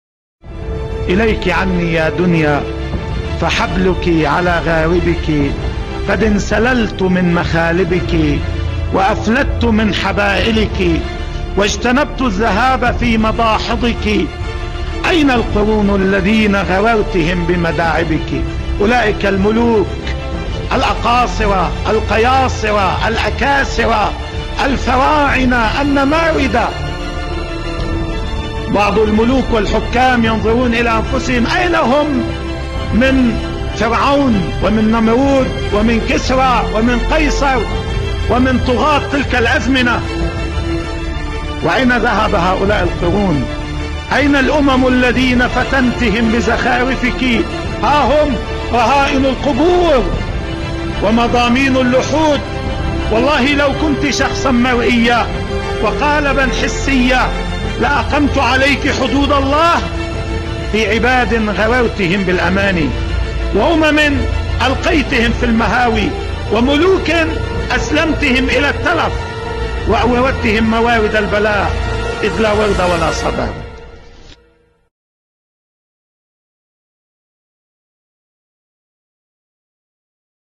إليك عني يا دنيا بصوت سماحة السيد حسن نصرالله